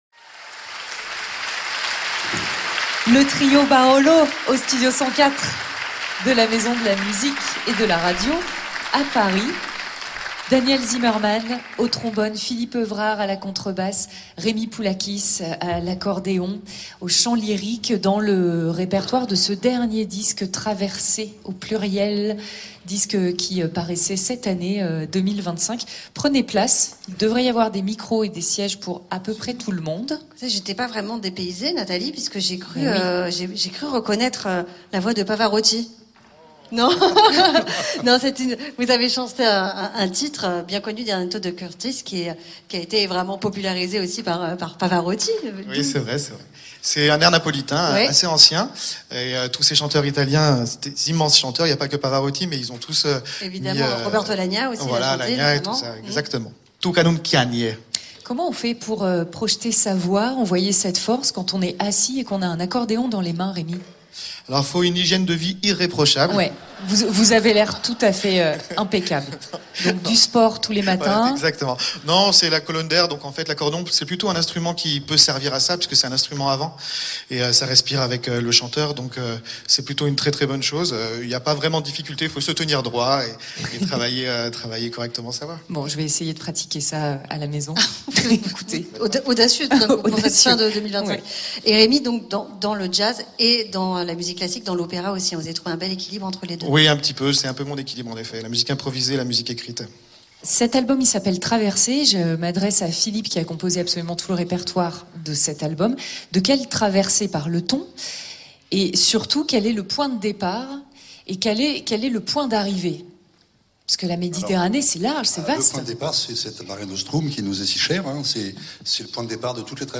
Radio France "Sur un Air de fête" - Interview du Trio Barolo